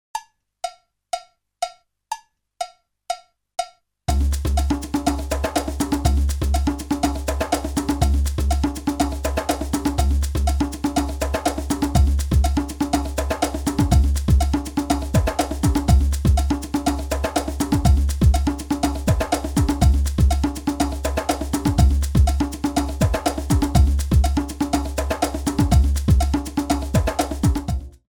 15 tracks of original percussion music
Nice consistent long tracks  - world music style